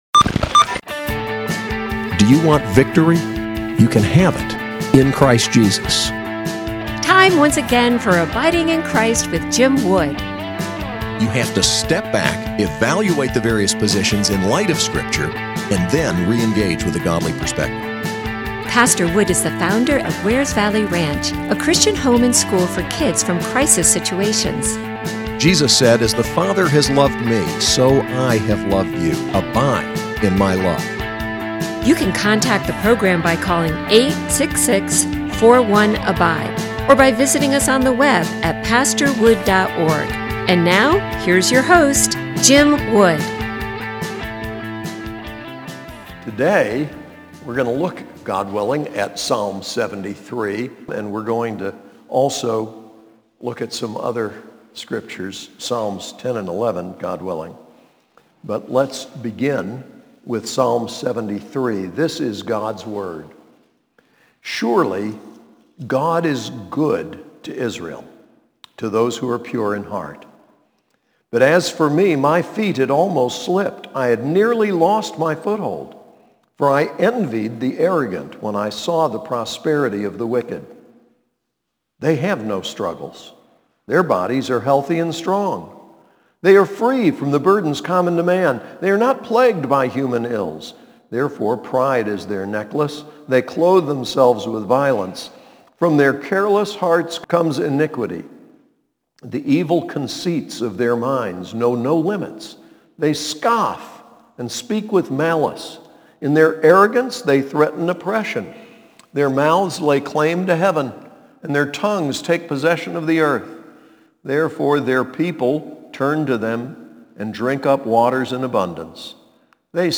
SAS Chapel: Psalms 73, 10, 11